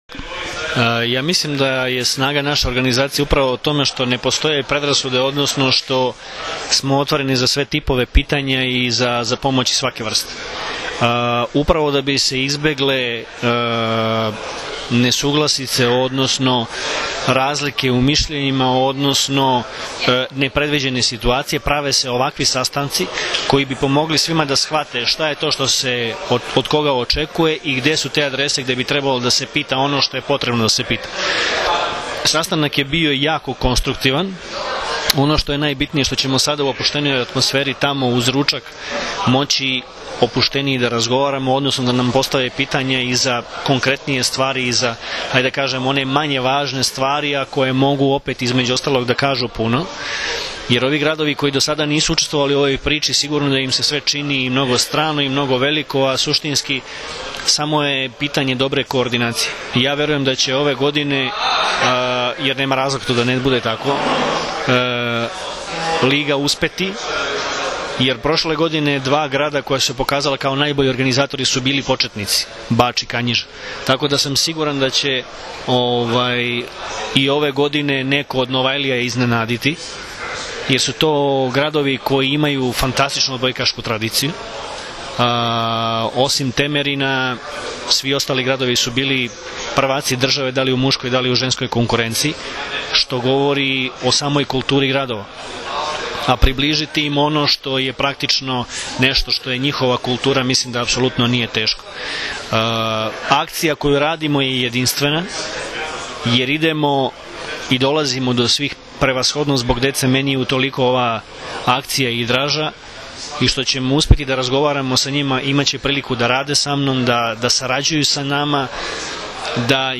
IZJAVA VLADIMIRA GRBIĆA, POTPREDSEDNIKA OSS